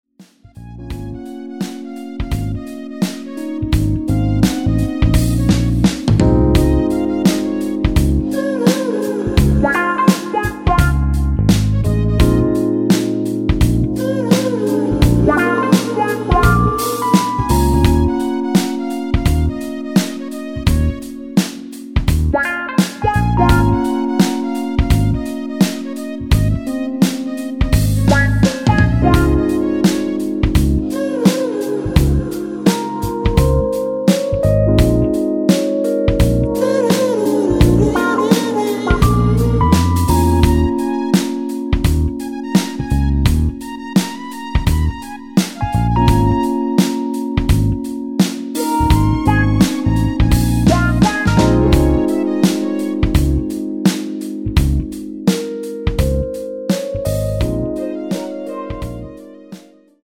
(중간 뚜루루루루~ 코러스 부분이 추가 되었습니다. 아래의 가사 부분 참조)
엔딩이 페이드 아웃이라 라이브에 사용 하기좋게 엔딩을 만들어 놓았습니다.(미리듣기 참조)
Db
앞부분30초, 뒷부분30초씩 편집해서 올려 드리고 있습니다.
중간에 음이 끈어지고 다시 나오는 이유는